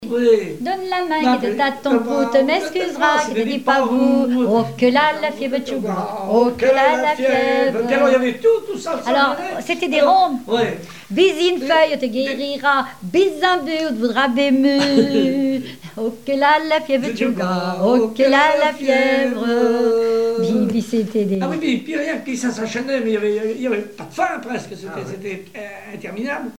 danse : ronde : la limouzine
chansons populaires et traditionnelles
Pièce musicale inédite